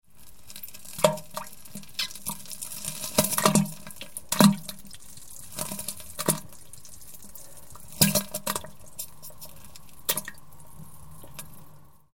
Звуки поноса
Женщина сидит на унитазе и с усилием выталкивает жидкое дерьмо под звуки диареи